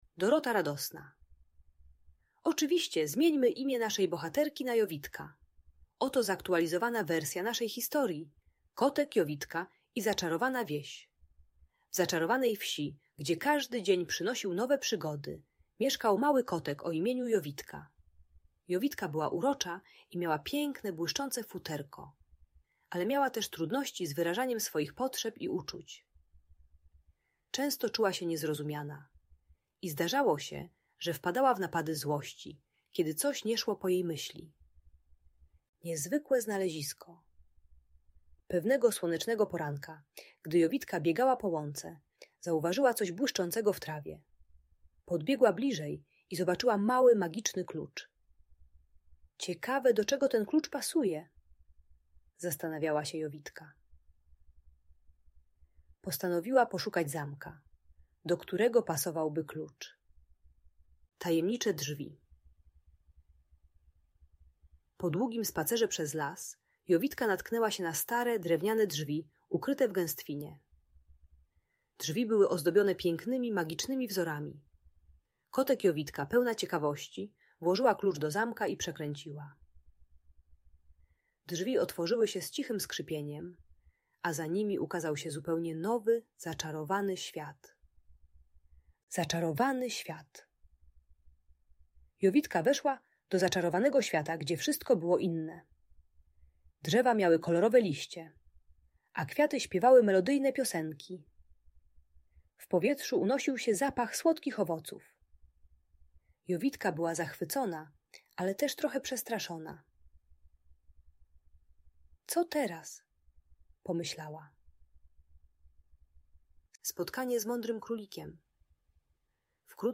Historia Kotek Jowitka i Zaczarowana Wieś - Audiobajka